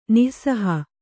♪ nih saha